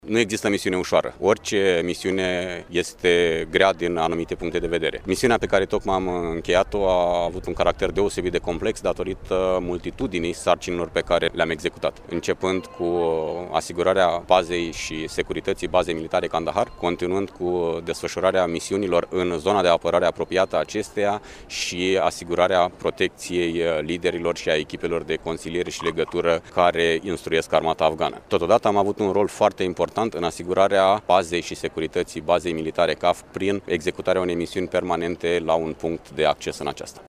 Ceremonia de întoarcere din teatrele de operaţiuni din Afganistan a soldaţilor din Batalionul 151 Infanterie a avut loc, astăzi, pe pietonalul Ştefan cel Mare din Iaşi, în prezenţa oficialităţilor locale, judeţene şi naţionale.